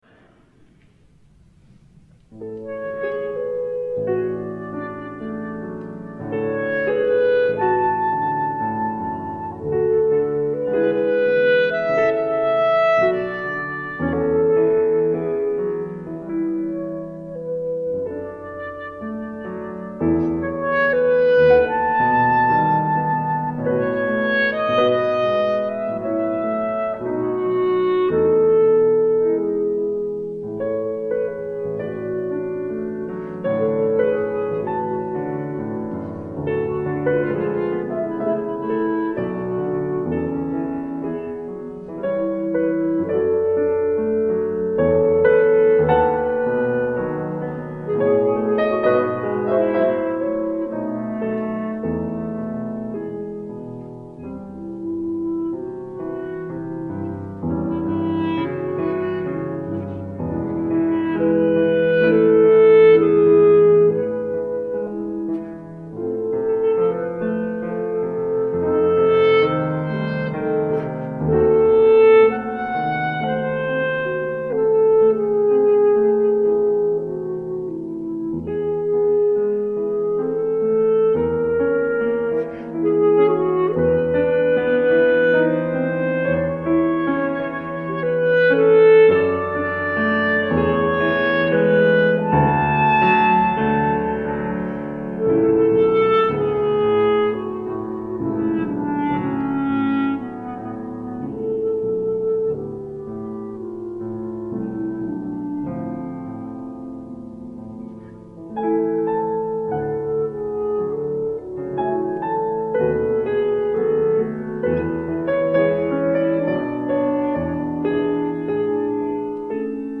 Clarinet (in A or B-flat), Piano